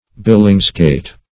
Billingsgate \Bil"lings*gate`\, n.